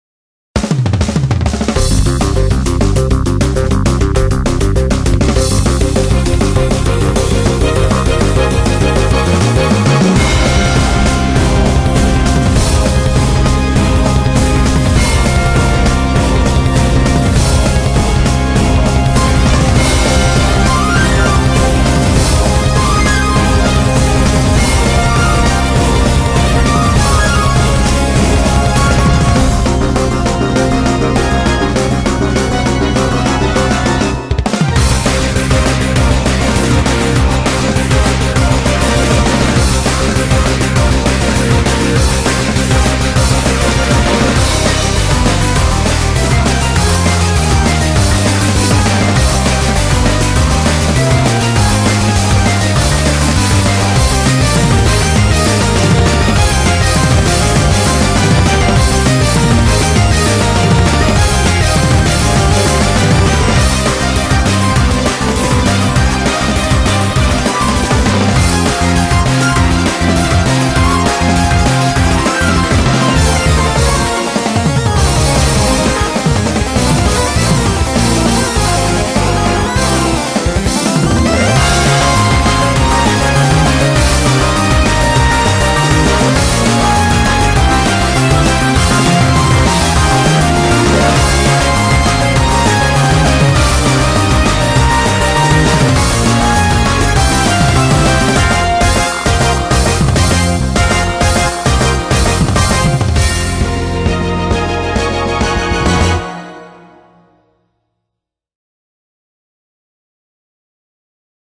BPM200
Audio QualityPerfect (High Quality)
themed after old-style game music.